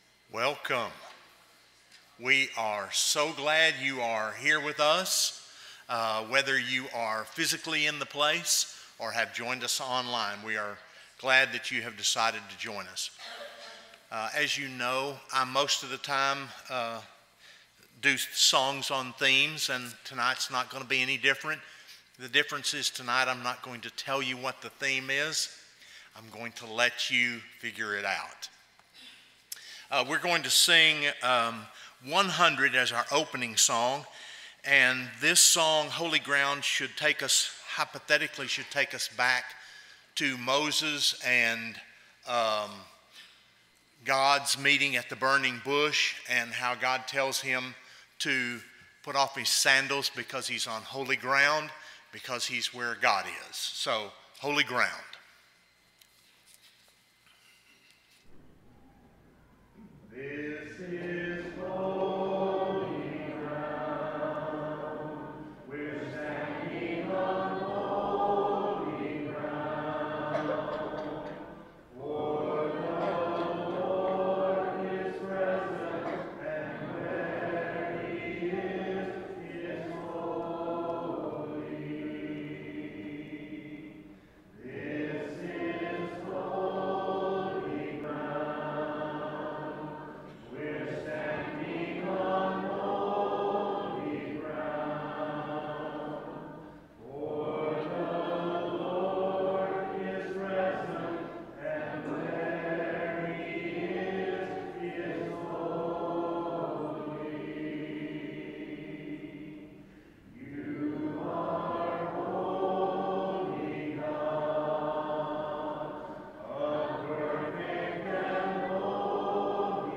(Hebrews 13:10), English Standard Version Series: Sunday PM Service